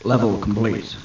Amiga 8-bit Sampled Voice
1 channel